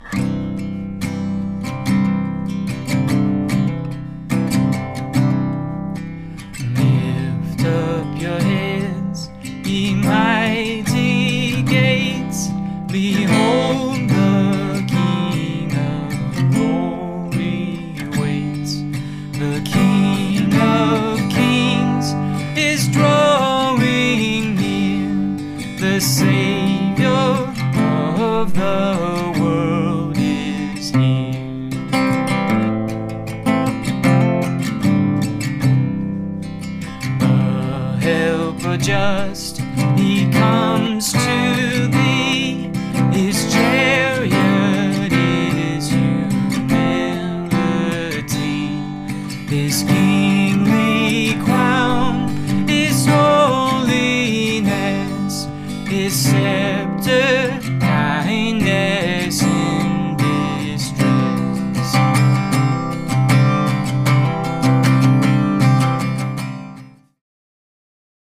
old familiar hymn